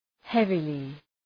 Προφορά
{‘hevılı}